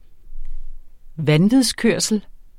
Udtale [ ˈvanviðs- ]